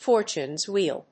アクセントFórtune's whéel＝the whéel of Fórtune